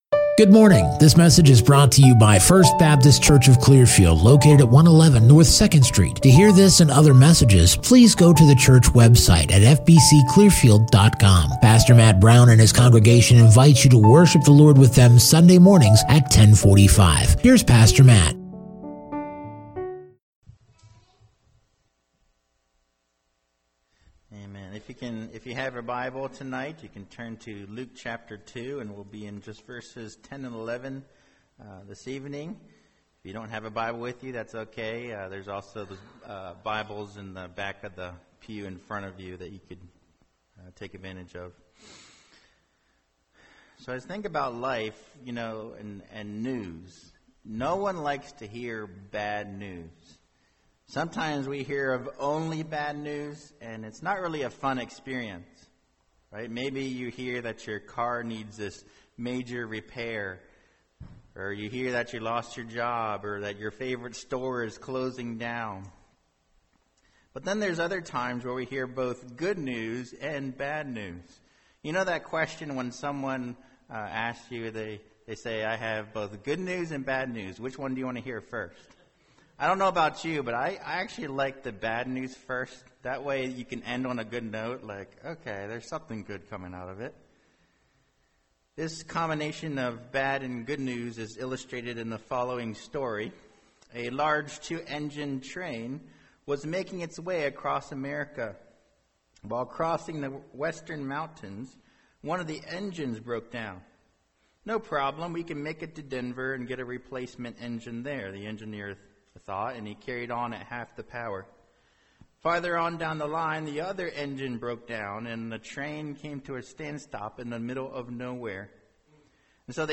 Non-Series Sermon